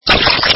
electrohaus/electroclash